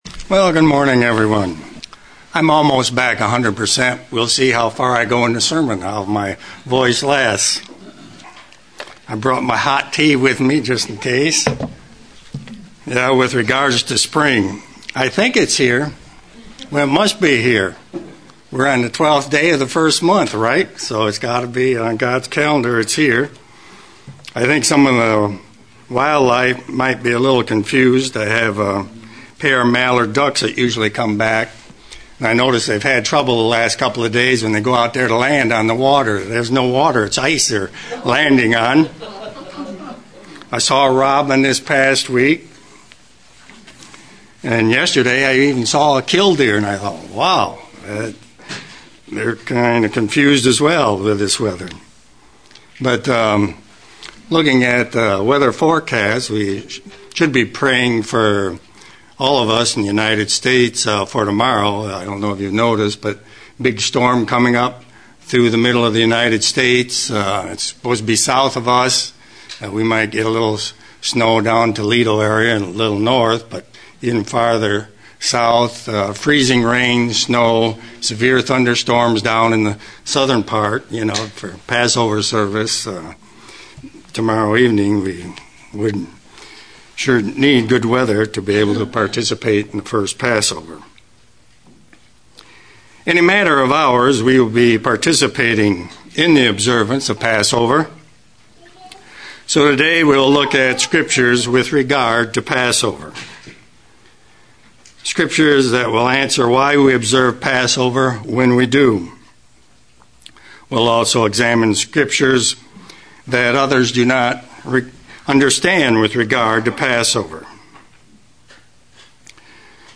Given in Ann Arbor, MI
UCG Sermon Studying the bible?